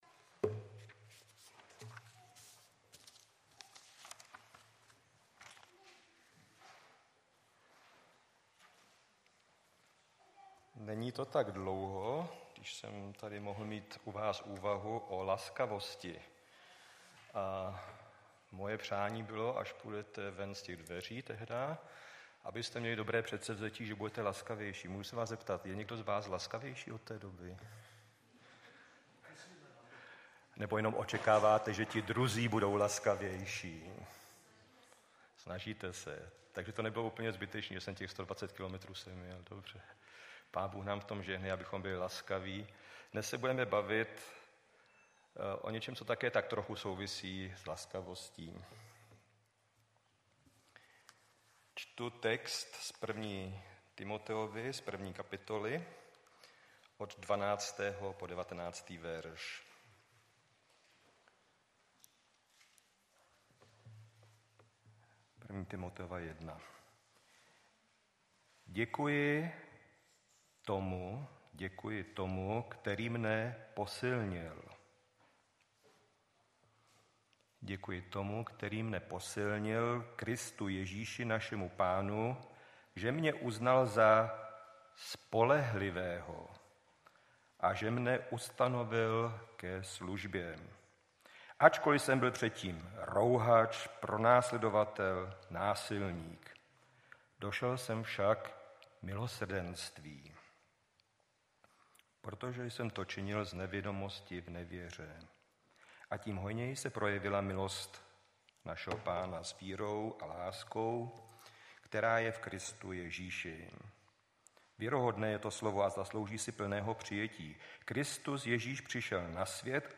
3.7.2016 v 10:57 do rubriky Kázání .